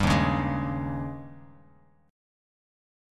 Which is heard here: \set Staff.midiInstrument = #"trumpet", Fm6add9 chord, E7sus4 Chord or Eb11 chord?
Eb11 chord